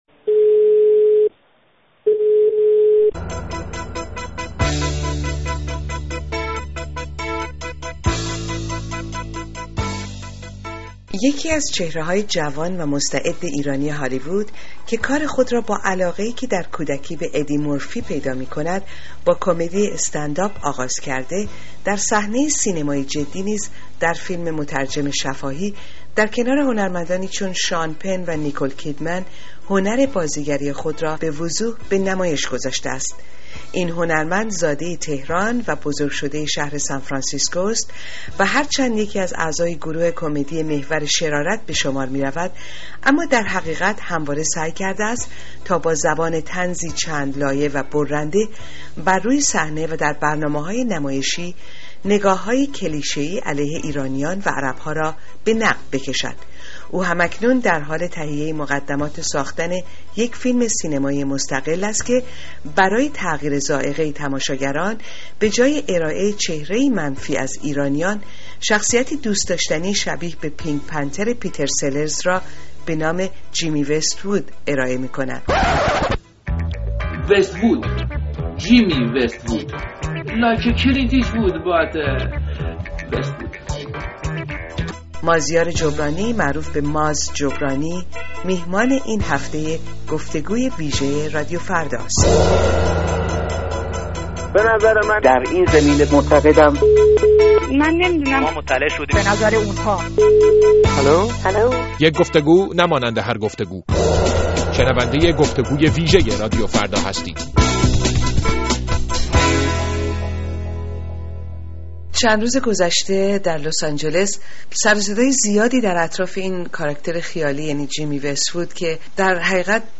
گفت و گوی ویژه